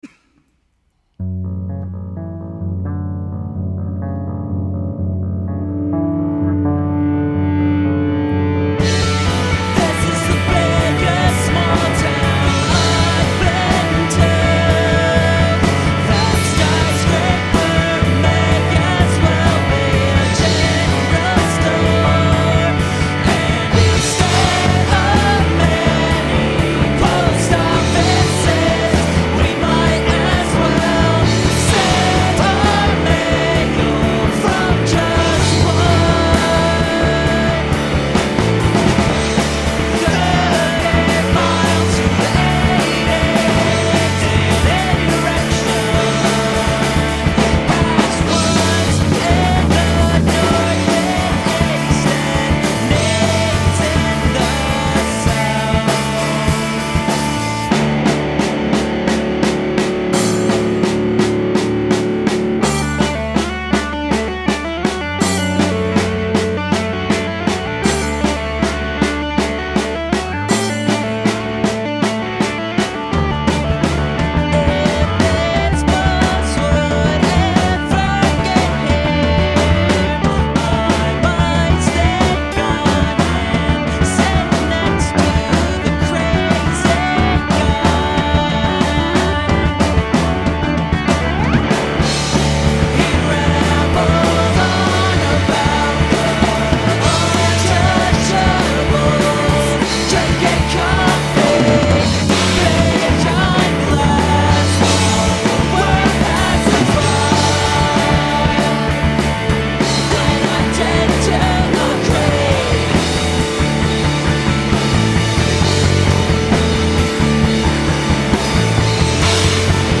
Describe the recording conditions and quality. recorded at bartertown studios